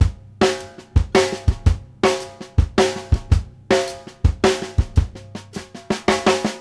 CLOSED HAT-L.wav